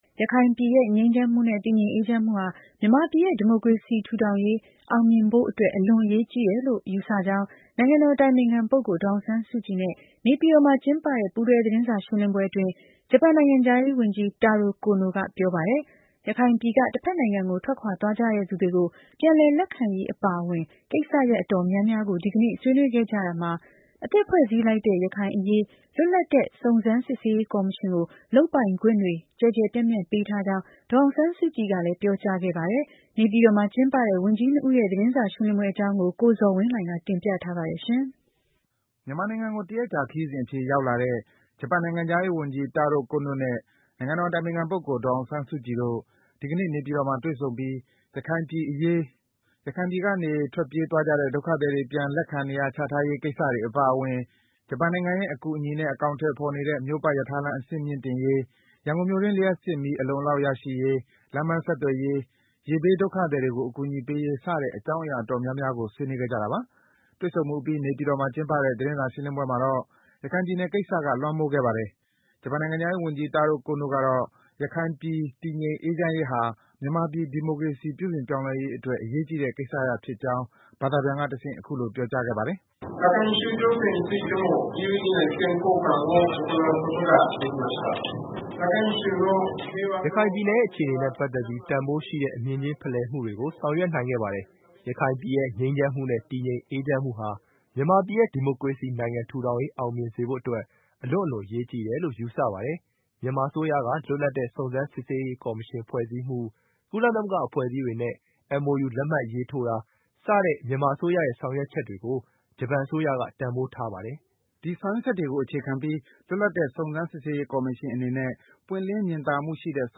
တွေ့ဆုံမှုအပြီး နေပြည်တော်မှာ ဒီကနေ့ ကျင်းပတဲ့ သတင်းစာရှင်းလင်းပွဲမှာတော့ ရခိုင်ပြည်နယ်ကိစ္စက လွမ်းမိုးခဲ့ပါတယ်။ ဂျပန်နိုင်ငံခြားရေးဝန်ကြီး တာရို ကိုနိုကတော့ ရခိုင်ပြည် တည်ငြိမ် အေးချမ်းရေးဟာ မြန်မာပြည် ဒီမိုကရေစီ ပြုပြင်ပြောင်းလဲရေးအတွက် အရေးကြီးတဲ့ ကိစ္စရပ်ဖြစ်ကြောင်း ဘာသာပြန်ကတဆင့် အခုလို ပြောကြားခဲ့ပါတယ်။